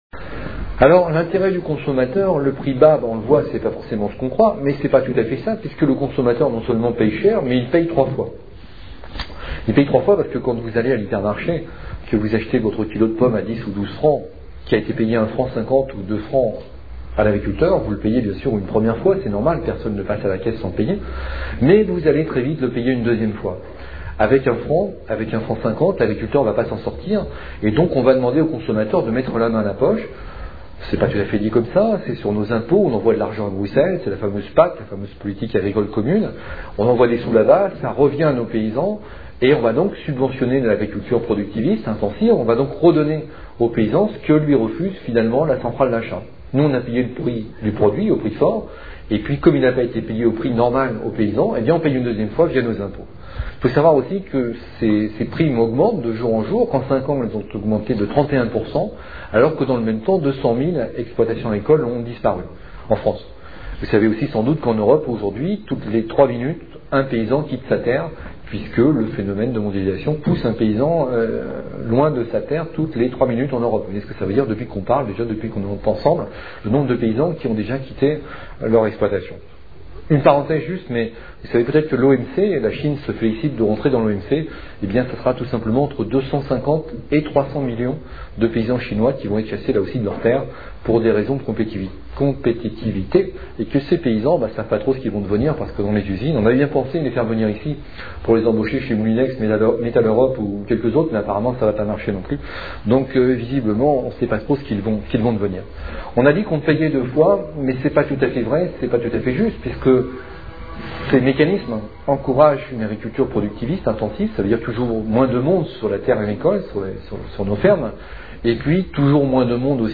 Conférence tenue le 18 août 2004 à Montpellier